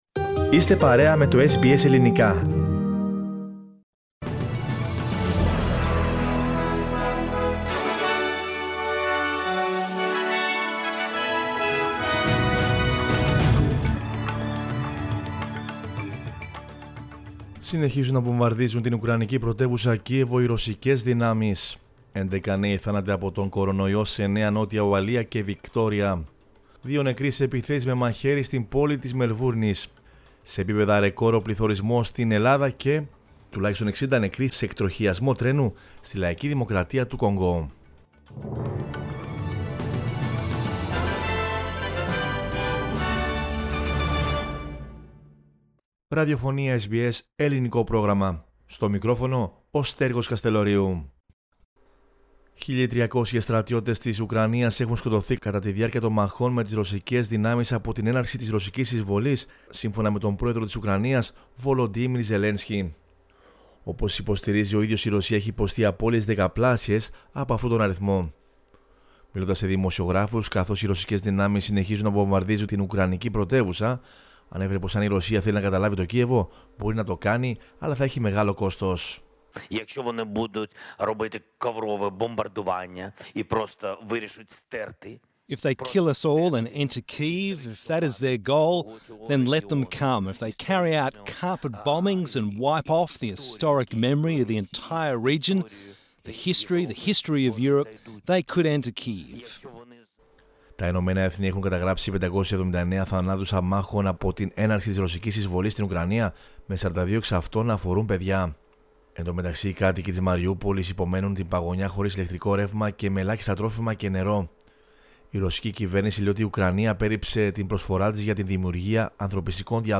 News in Greek from Australia, Greece, Cyprus and the world is the news bulletin of Sunday 13 March 2022.